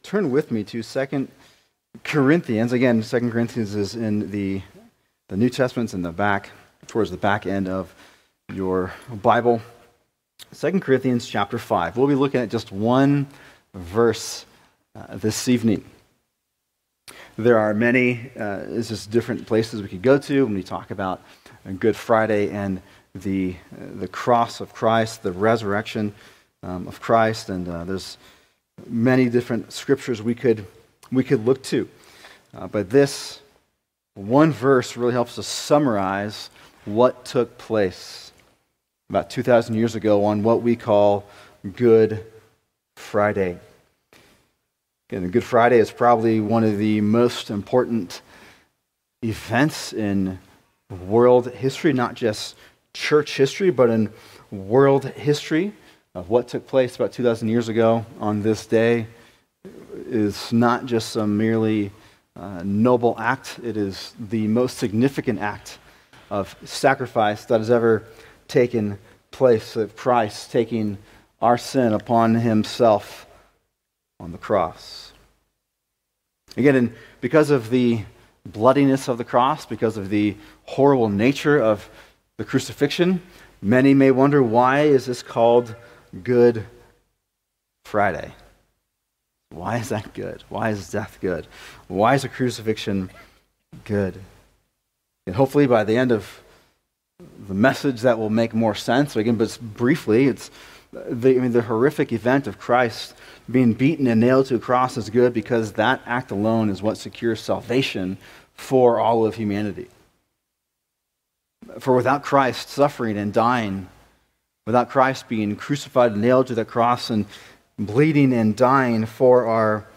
[Sermon] 2 Corinthians 5:21 How to be Reconciled with God (Good Friday) | Cornerstone Church - Jackson Hole